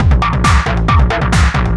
DS 136-BPM A4.wav